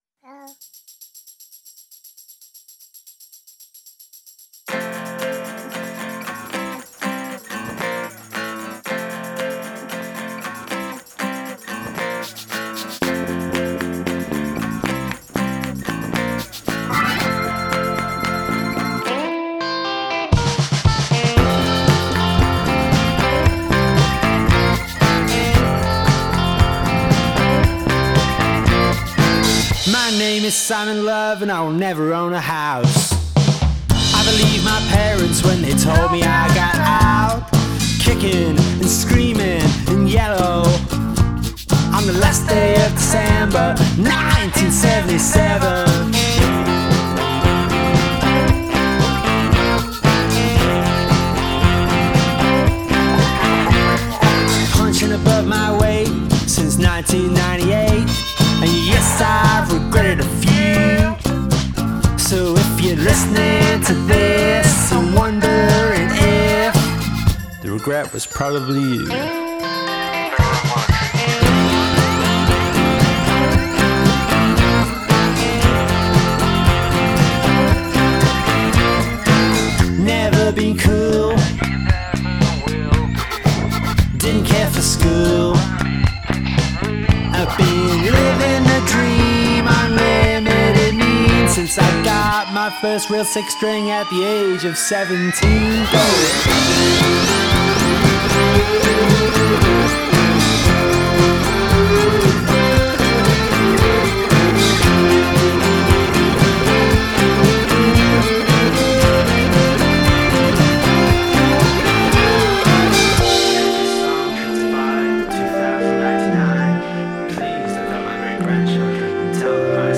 with a spot-on Velvet Underground groove